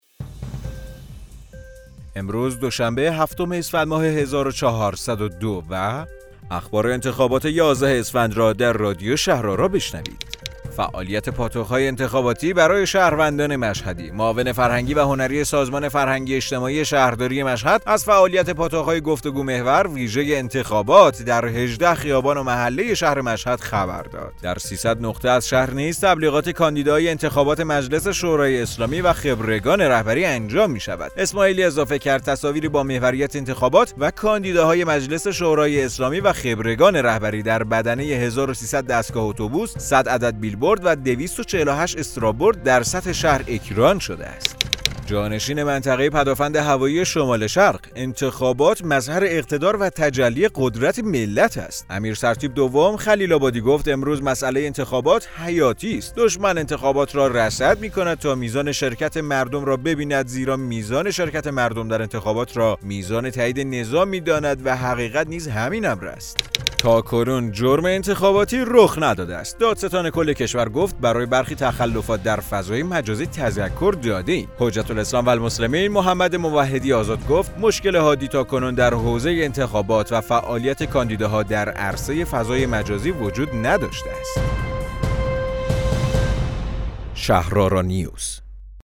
رادیو شهرآرا، پادکست خبری انتخابات یازدهم اسفندماه است.